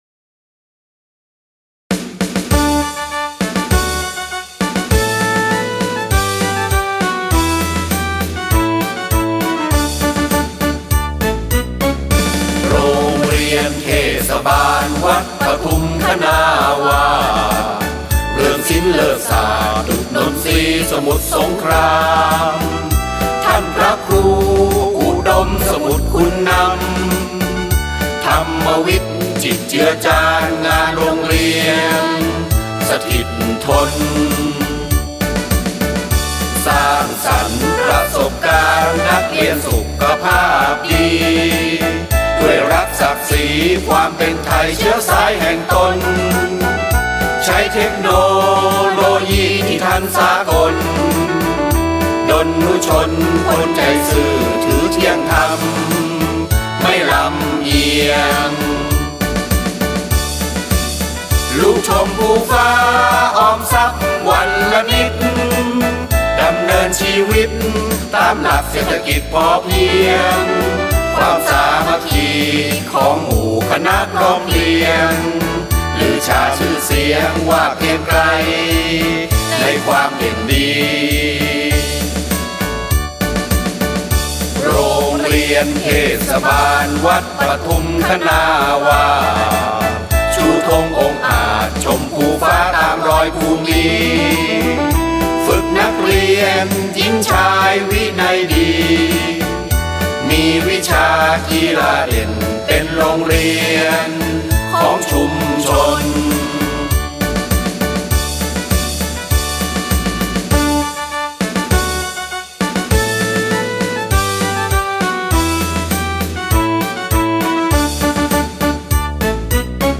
เพลงมาร์ชโรงเรียน